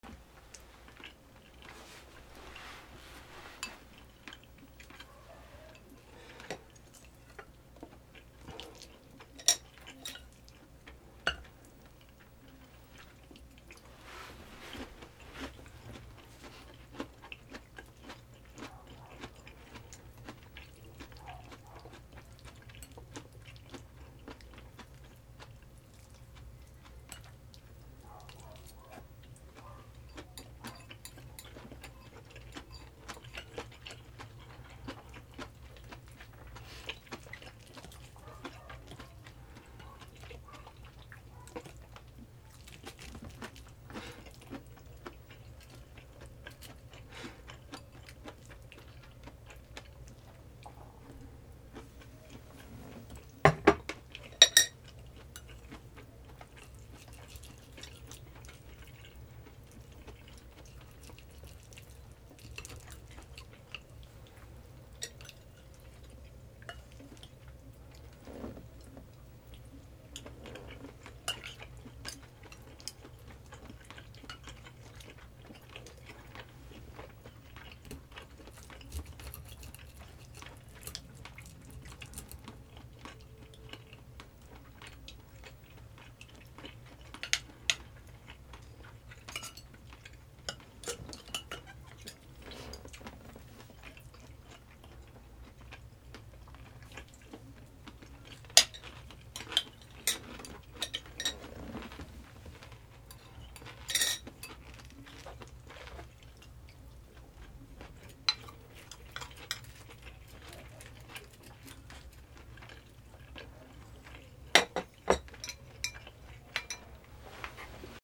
プラスチック食器で食事 4